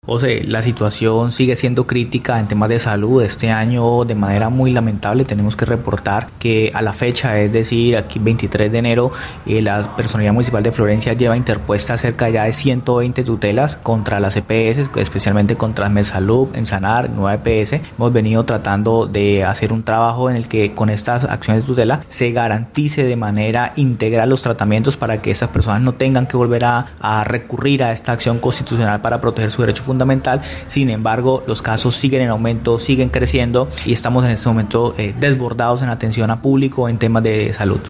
Jorge Luis Lara, personero del municipio de Florencia, explicó que las tutelas, van dirigidas especialmente a las EPS ASMET Salud, Ensanar y Nueva EPS, de los regímenes subsidiado y contributivo.